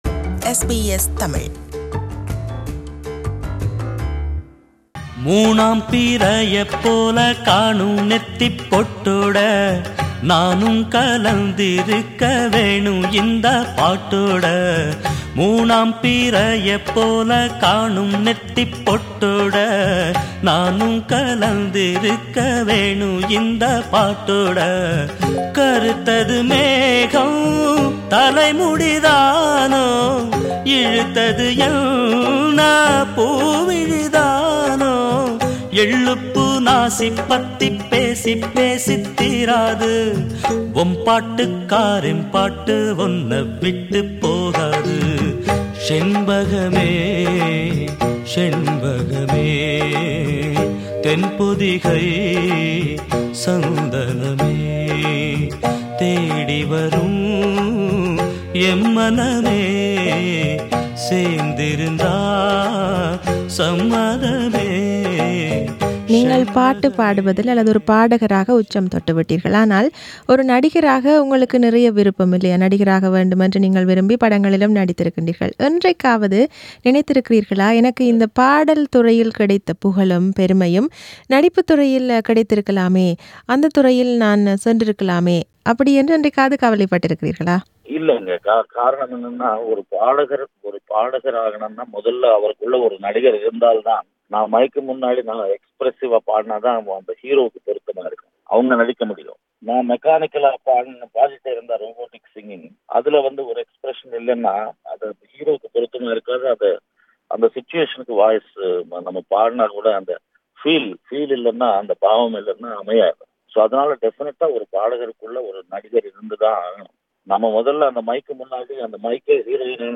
An Interview with Mano: P02
Mano will perform in Raja Rajathaan concert in Sydney. This is an interview with him.